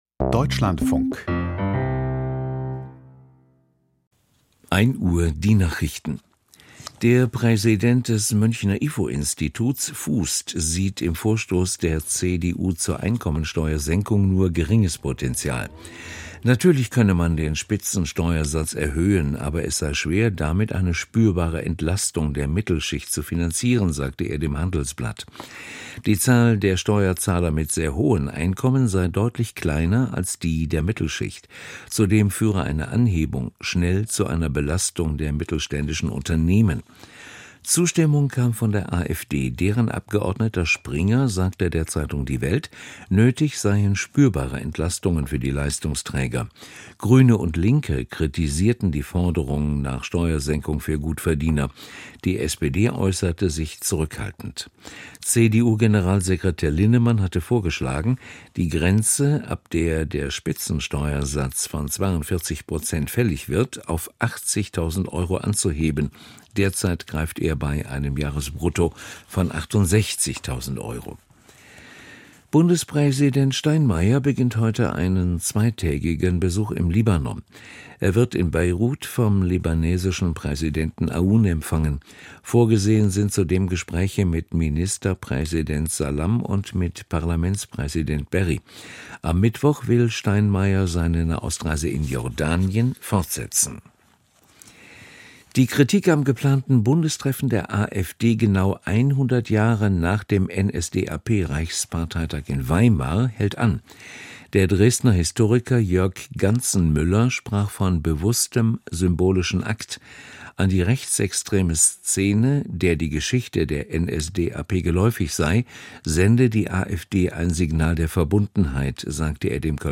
Die Nachrichten vom 16.02.2026, 01:00 Uhr